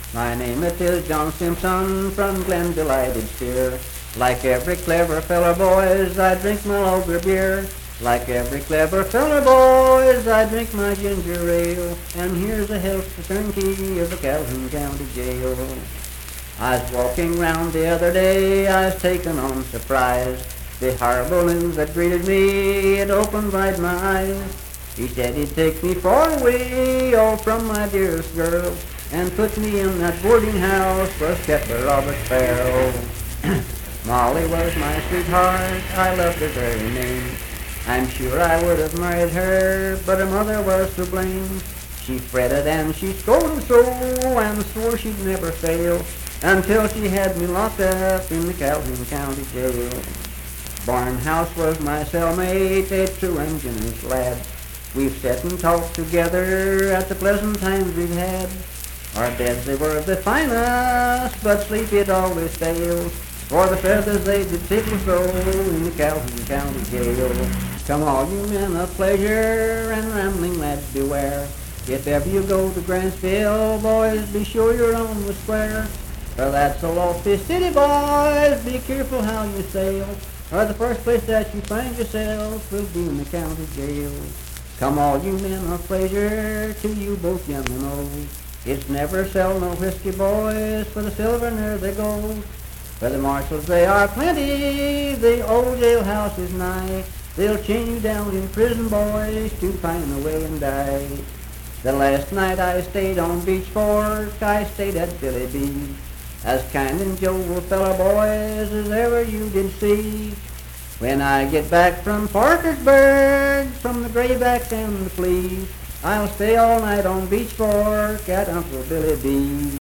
Unaccompanied vocal music performance
Verse-refrain 7(4).
Voice (sung)